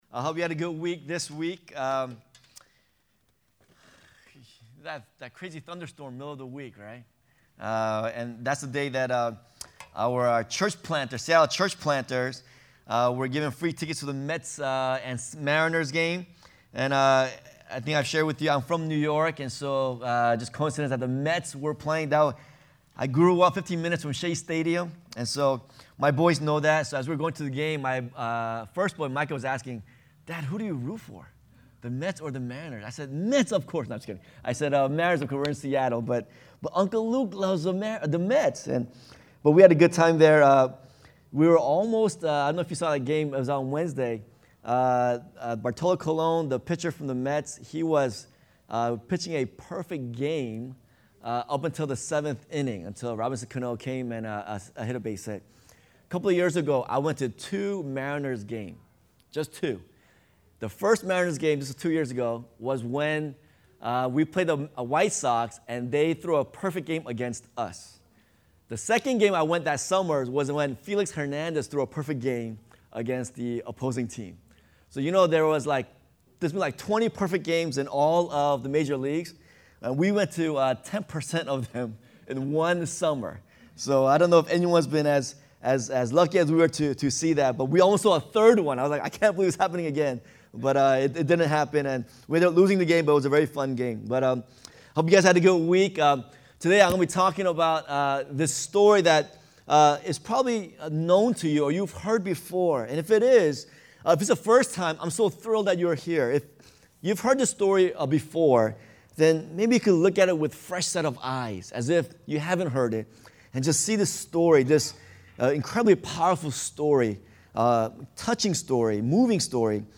sermons - Church of the Beloved